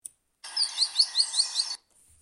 waldwasserlaeufer.mp3